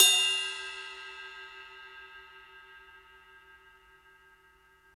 Index of /90_sSampleCDs/Roland L-CDX-01/CYM_Rides 1/CYM_Ride menu